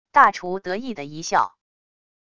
大厨得意的一笑wav音频